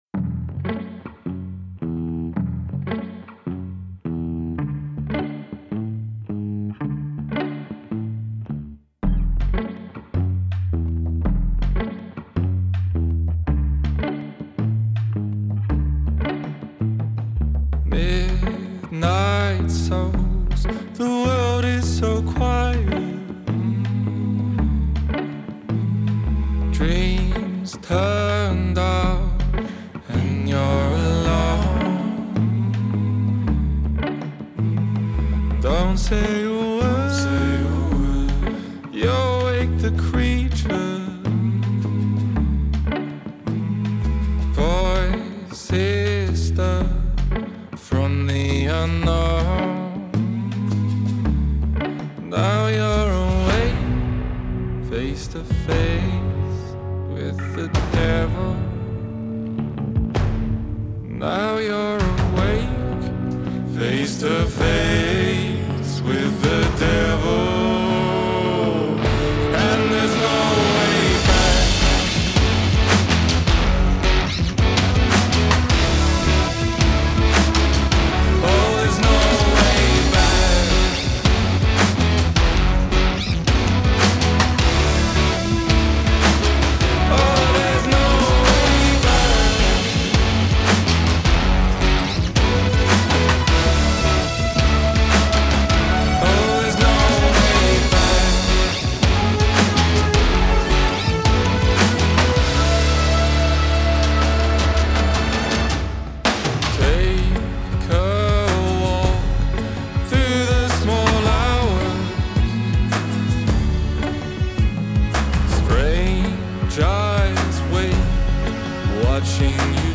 tema dizi müziği, duygusal heyecan enerjik fon müziği.